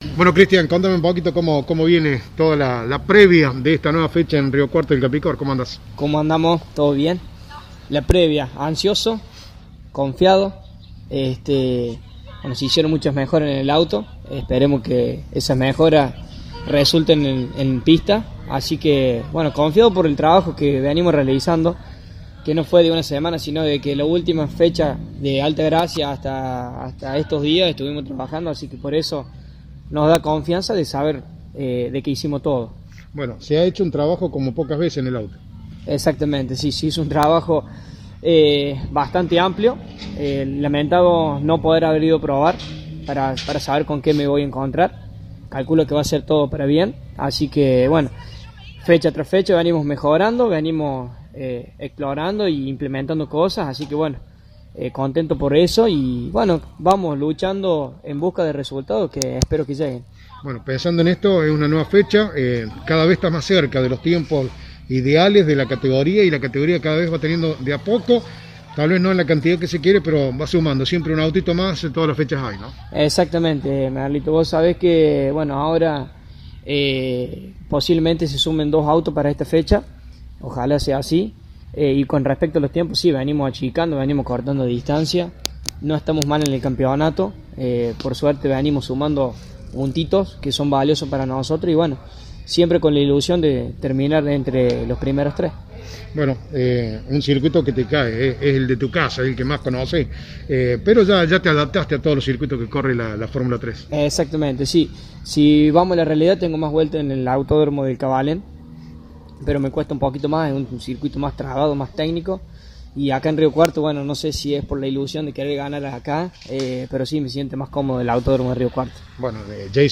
En una franca conversación